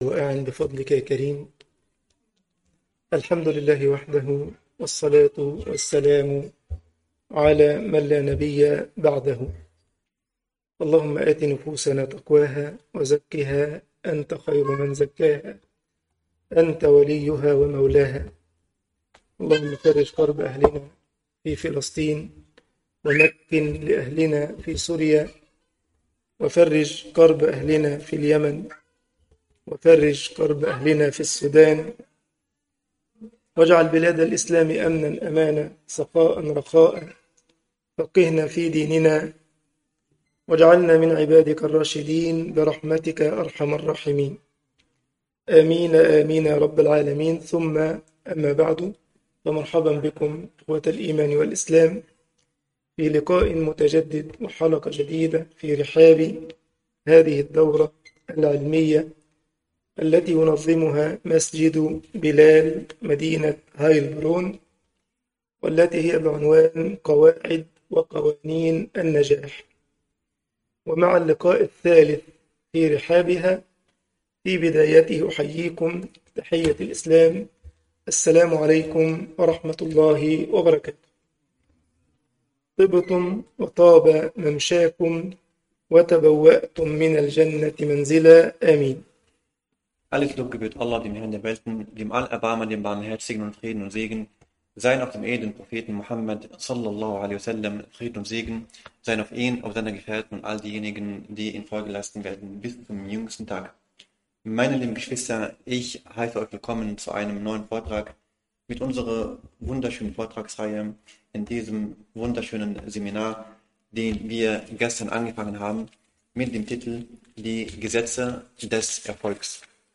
المحاضرة 3